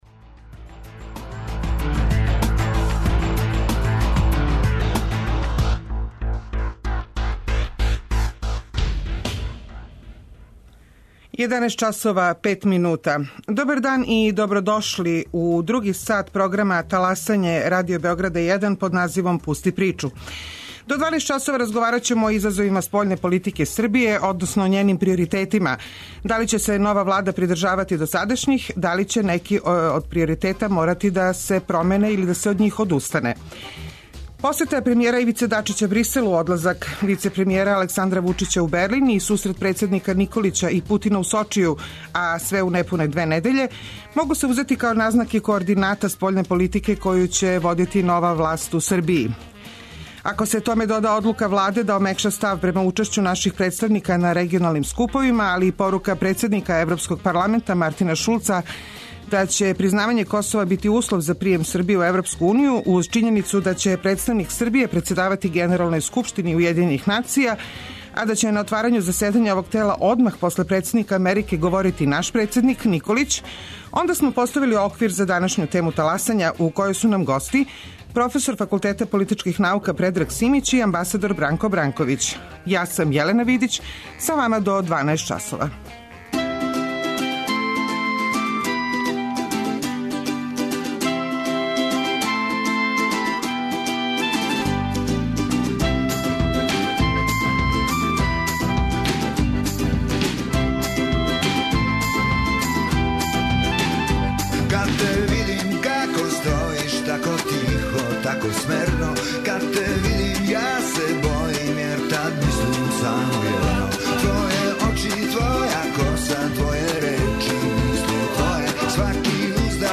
Радио Београд 1, 11.05